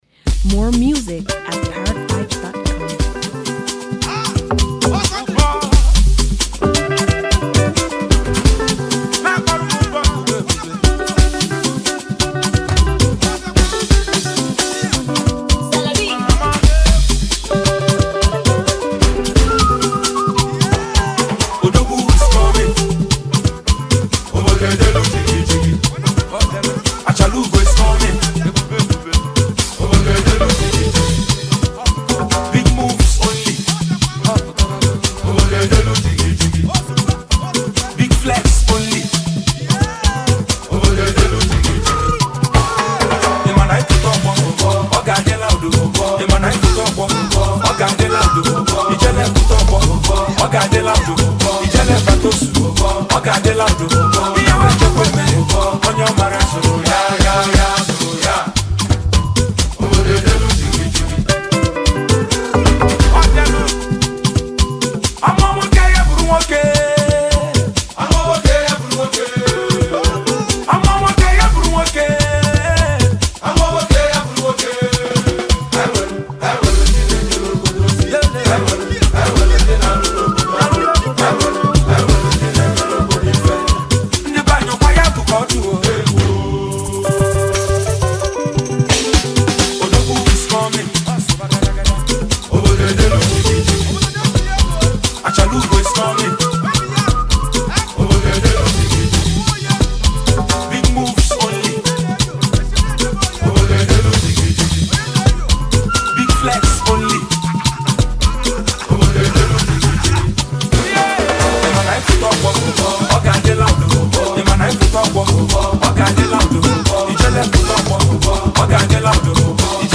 Incredibly talented Nigerian singer and songwriter